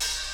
• Classic Raw Hip-Hop Open High-Hat A Key 01.wav
Royality free open hi hat tuned to the A note. Loudest frequency: 5469Hz
classic-raw-hip-hop-open-high-hat-a-key-01-6i3.wav